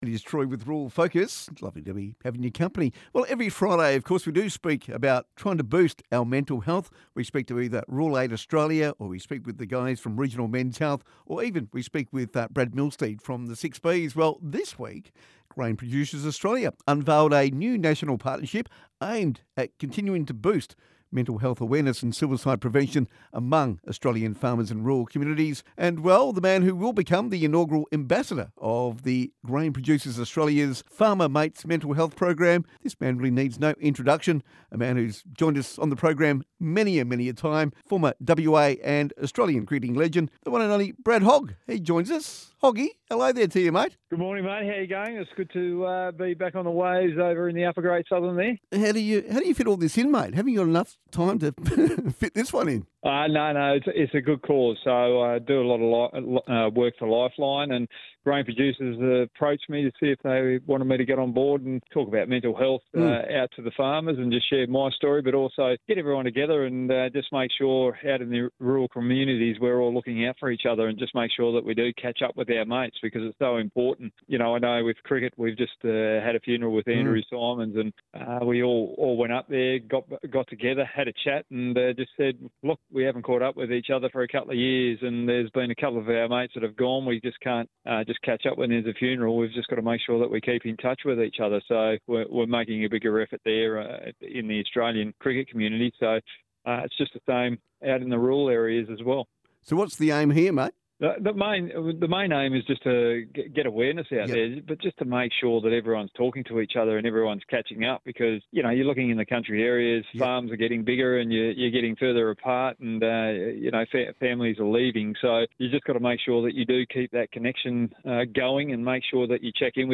RURAL FOCUS | Interview with Brad Hogg, Farmer Mates Mental Health Ambassador